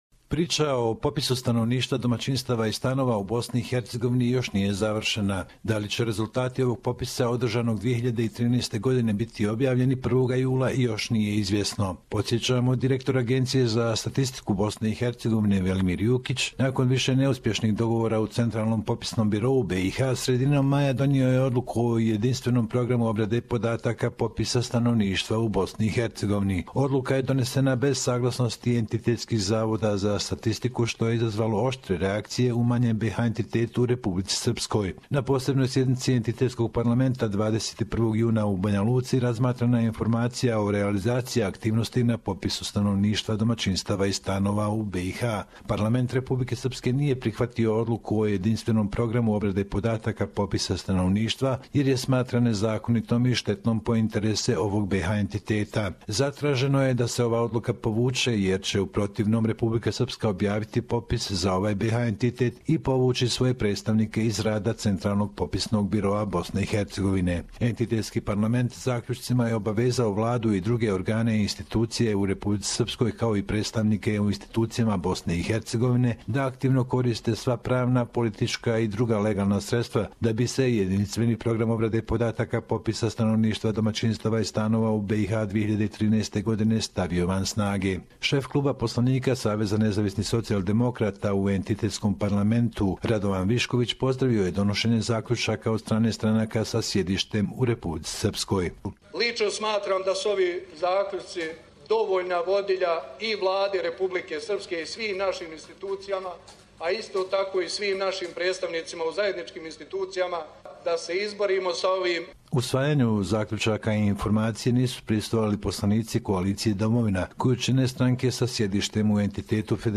Report from Bosnia and Herzegovina Census results publishing - unanimously rejected by the RS National Assembly.